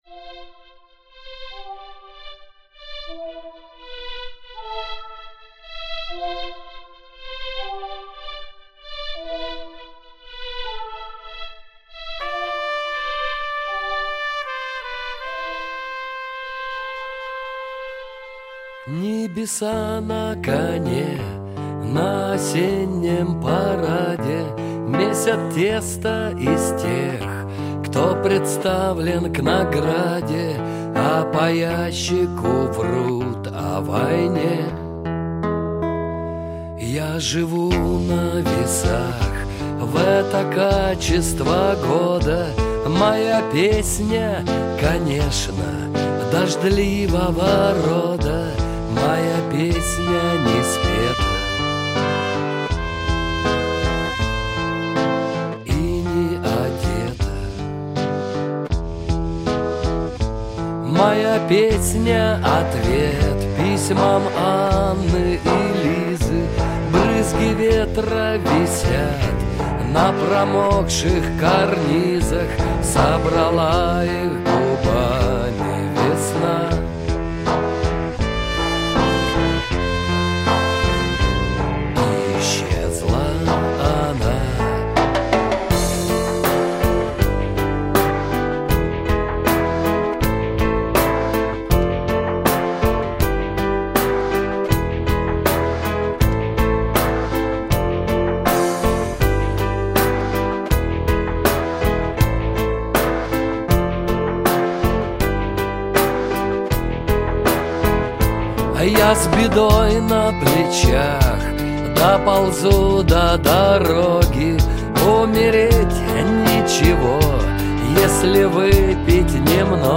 Длительность 4:47, стерео